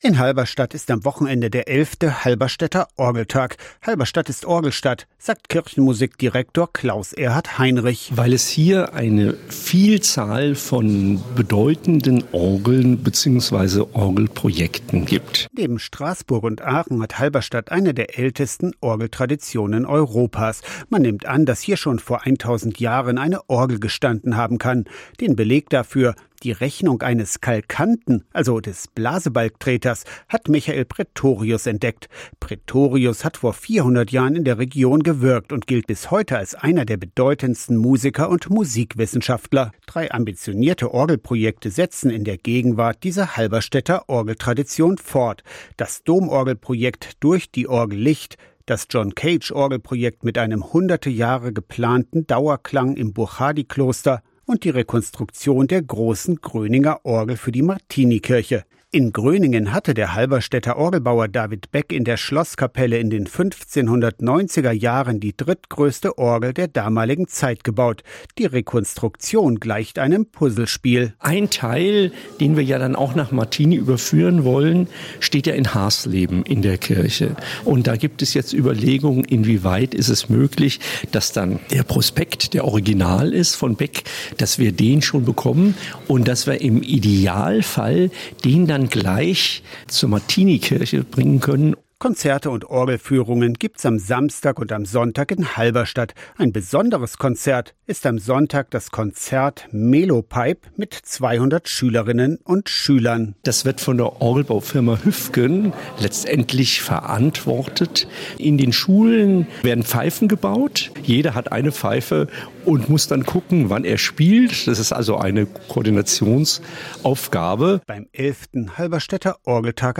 radio SAW 05.09.2019 04:58 As slow as possible – John-Cage-Komposition klingt über 600 Jahre Am Wochenende ist Orgeltag in Halberstadt, das als so etwas wie die heimliche Orgelhauptstadt gilt.